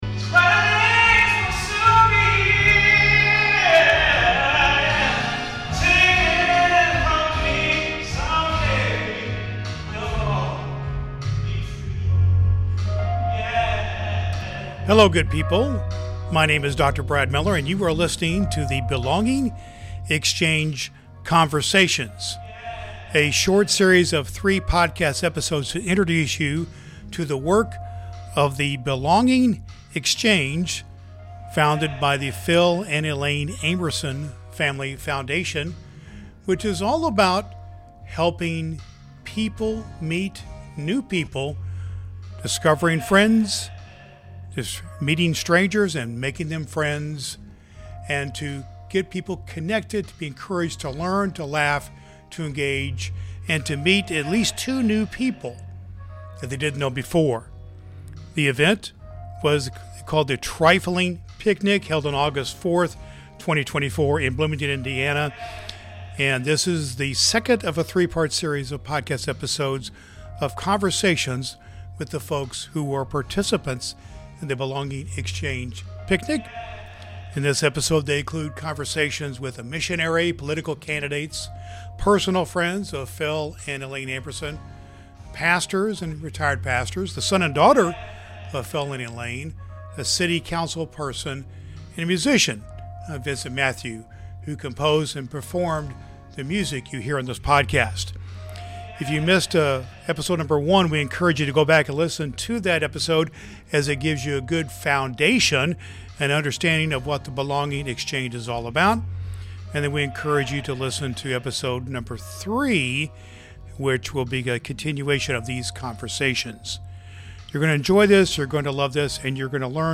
In this second installment of our three-part series introducing the enriching work of the Belonging Exchange, we delve into the conversations and experiences from the Trifling Picnic held in Bloomington, Indiana.
This episode (02) features an illuminating discussion with an eclectic array of guests.